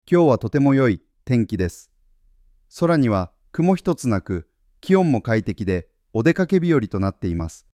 AIが自然な間や抑揚をつけるためには、句読点が重要です。